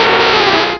pokeemerald / sound / direct_sound_samples / cries / nidoqueen.aif
-Replaced the Gen. 1 to 3 cries with BW2 rips.
nidoqueen.aif